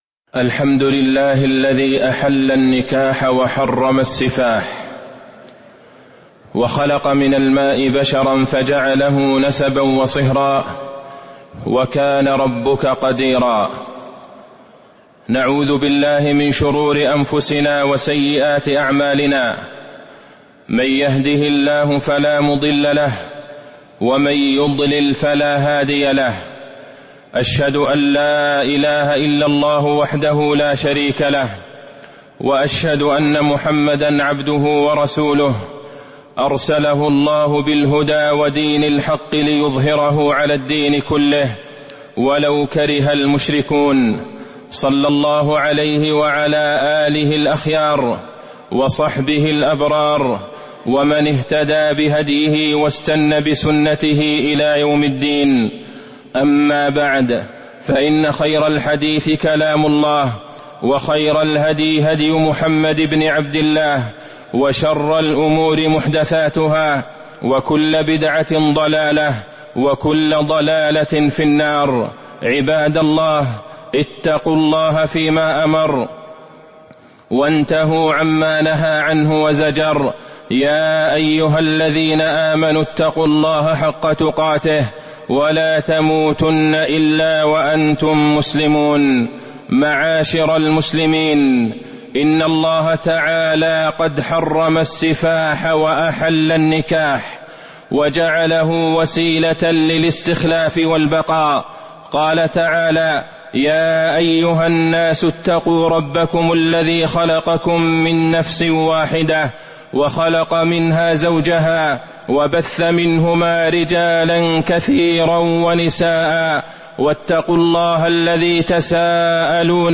تاريخ النشر ١ جمادى الأولى ١٤٤٤ هـ المكان: المسجد النبوي الشيخ: فضيلة الشيخ د. عبدالله بن عبدالرحمن البعيجان فضيلة الشيخ د. عبدالله بن عبدالرحمن البعيجان ألا واستوصوا بالنساء خيراً